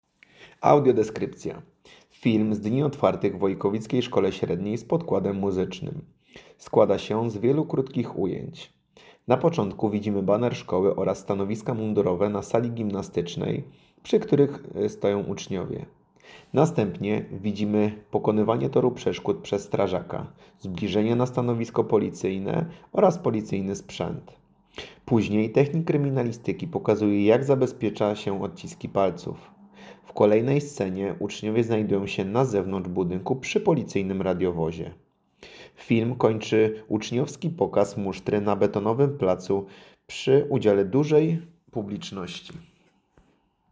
Nagranie audio Audiodeskrypcja.mp3